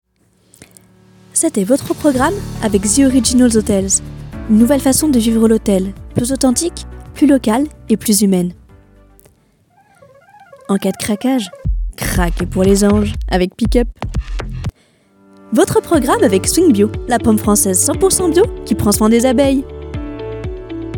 Voix off
maquette billboard